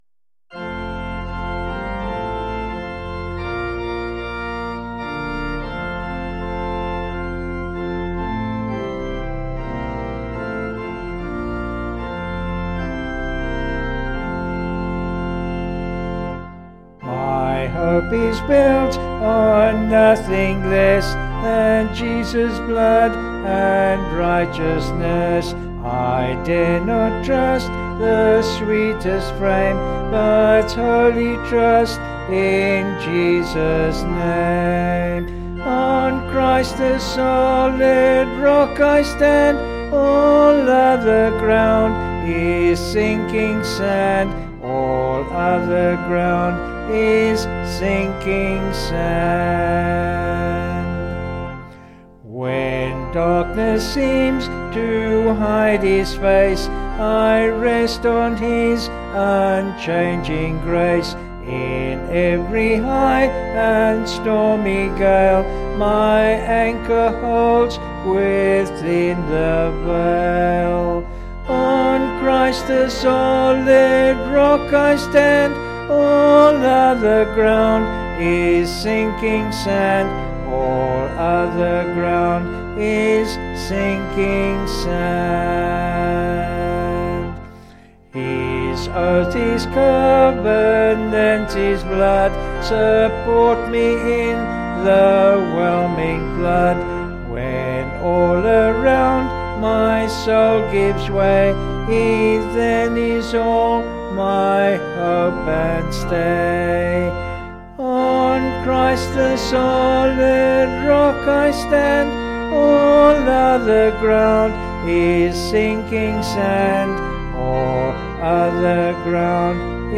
Vocals and Organ   263.5kb Sung Lyrics